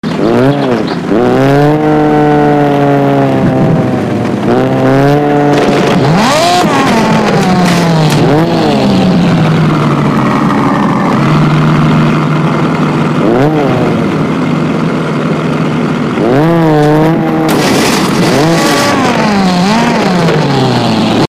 Car Parking Gameplay 🚗 | Sound Effects Free Download